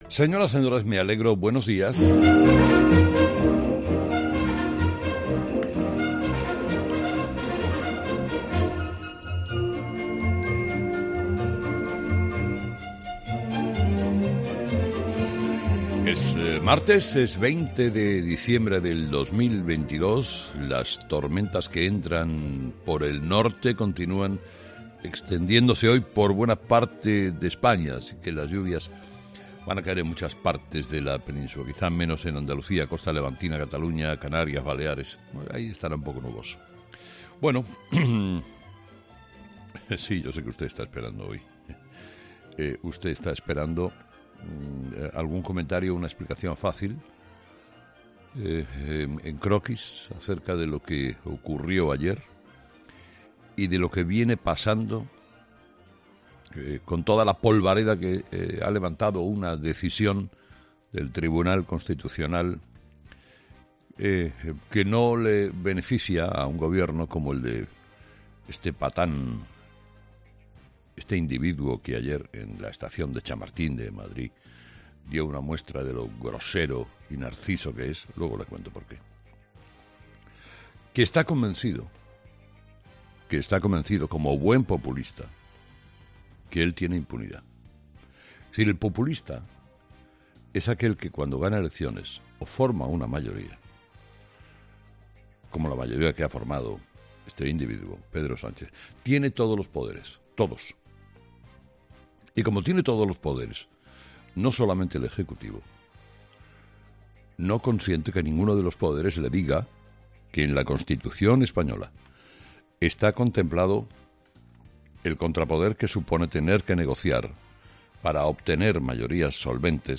El director de 'Herrera en COPE' analiza los principales titulares que marcarán la actualidad de este martes 20 de diciembre